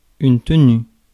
Ääntäminen
IPA : [ˈmeɪn.tɪ.nəns]